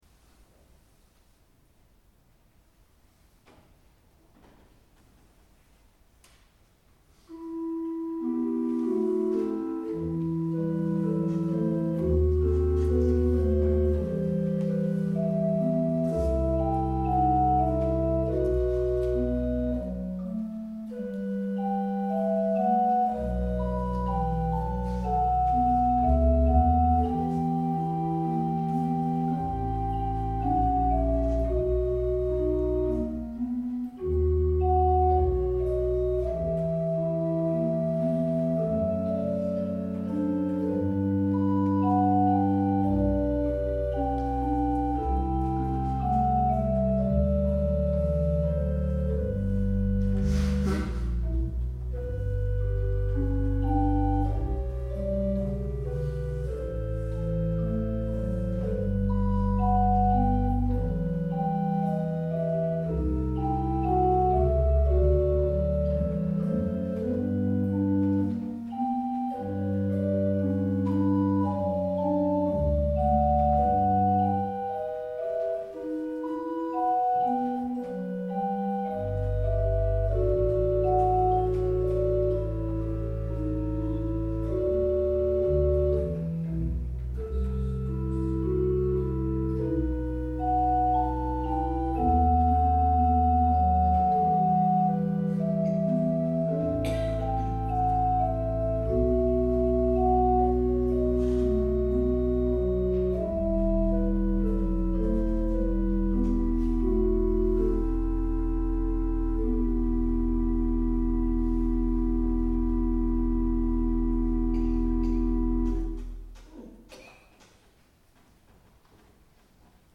Bordenau Ev.-luth. St. Thomas-Kirche
Stimmung nach Bach-Kellner
Klangbeispiele dieser Orgel